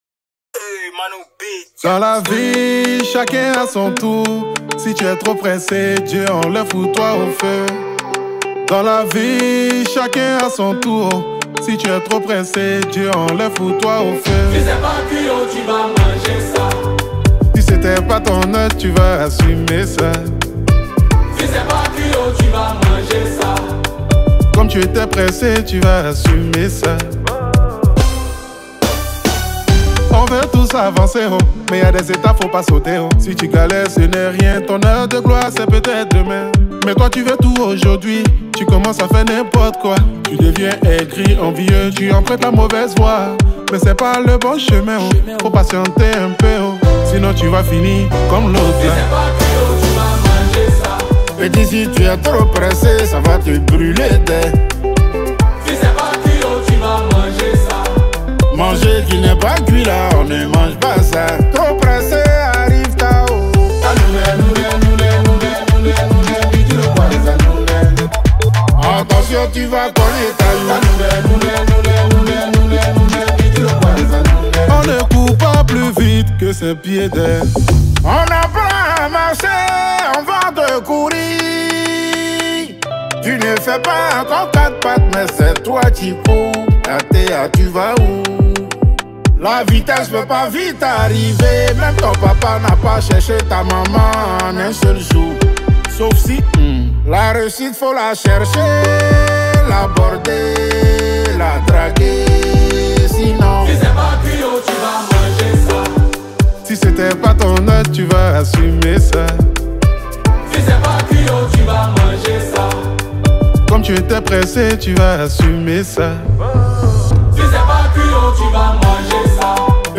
| Zouglou